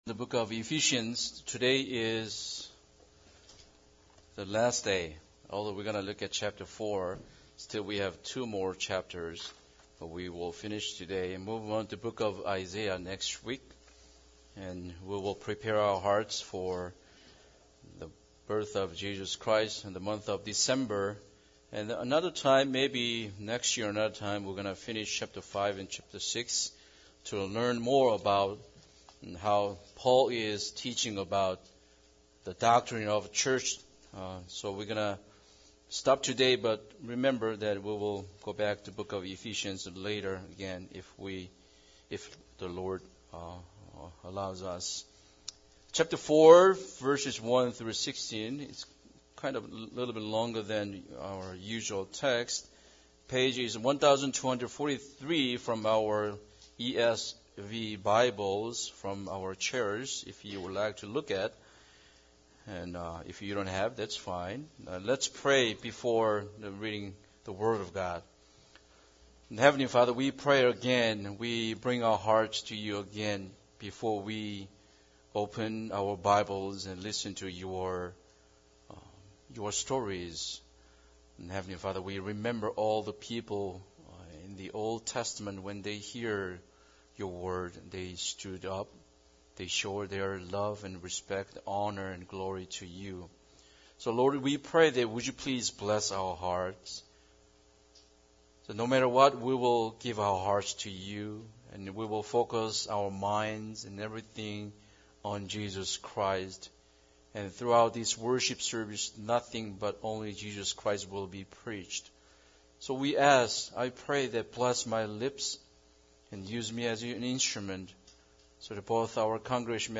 Ephesians 4:1-16 Service Type: Sunday Service Bible Text